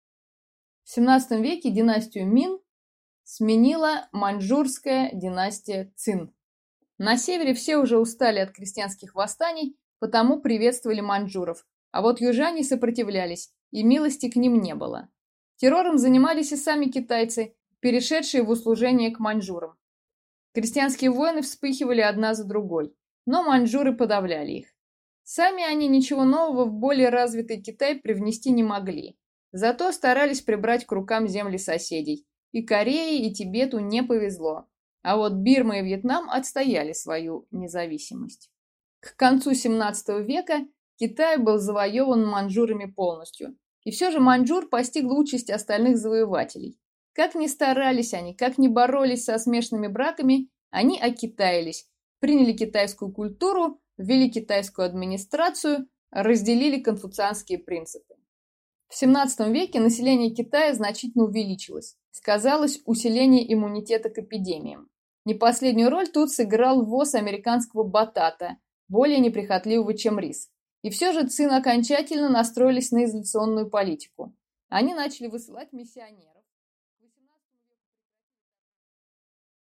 Аудиокнига Китай. От Цин до наших дней | Библиотека аудиокниг